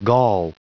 Prononciation du mot gall en anglais (fichier audio)
Prononciation du mot : gall